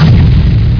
Rock.au